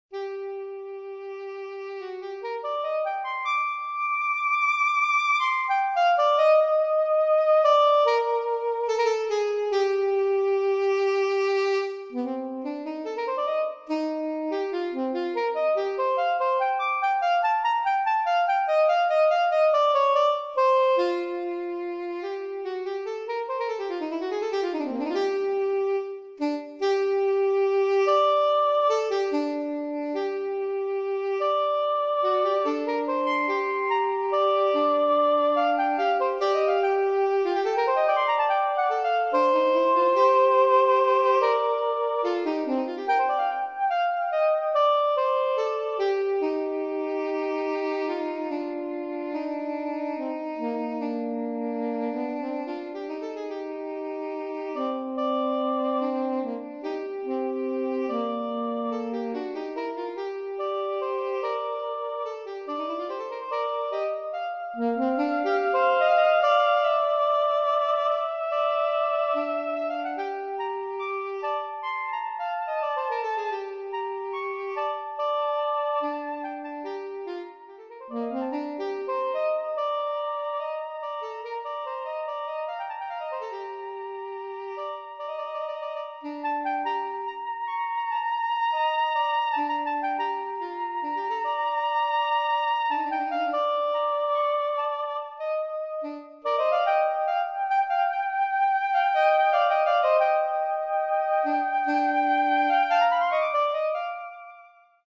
Duo/trio/quator pour saxophones équivalents.